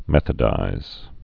(mĕthə-dīz)